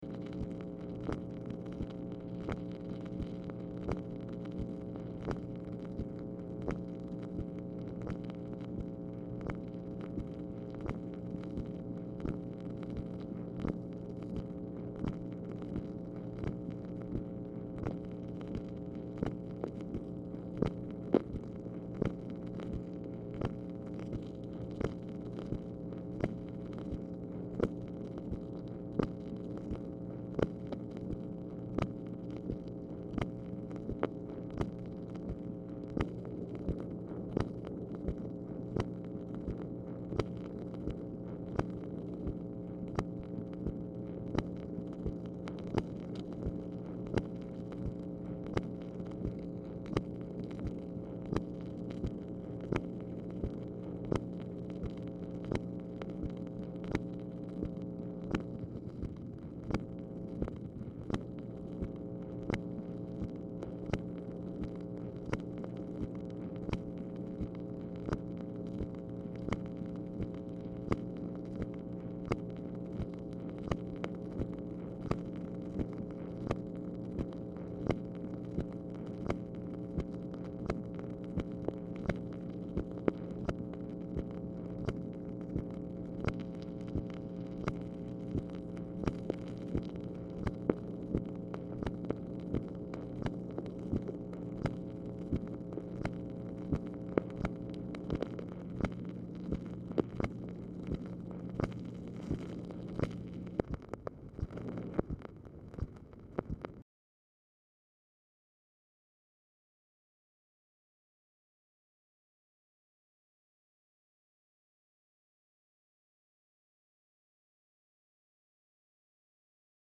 Telephone conversation # 6851, sound recording, MACHINE NOISE, 2/16/1965, time unknown | Discover LBJ
Telephone conversation
Dictation belt